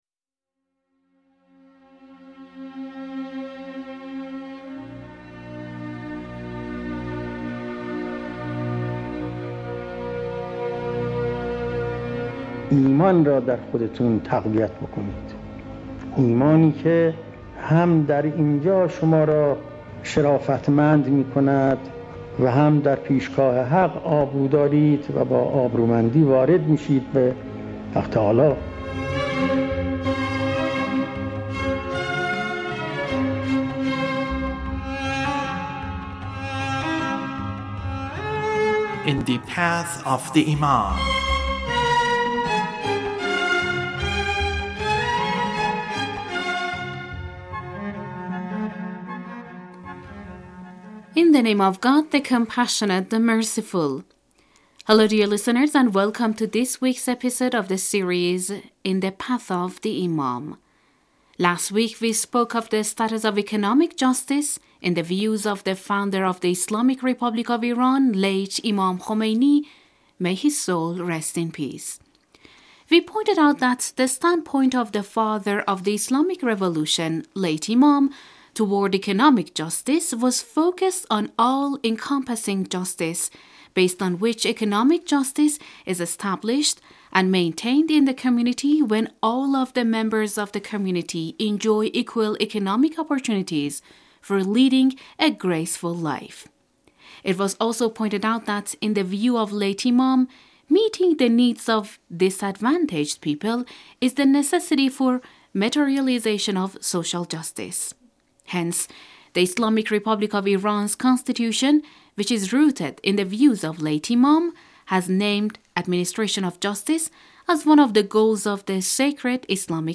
The radio series sheds light on the life of the Founder of the Islamic Republic of Iran, Imam Khomeini (God bless his soul) and his struggles against the Shah's despotic regime aimed at bringing about independence and freedom for the Iranian nation.